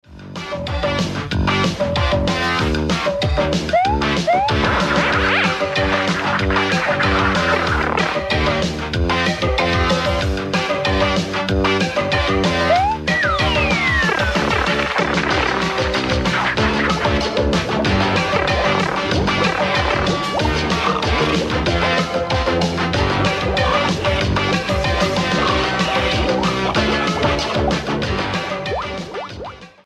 Music sample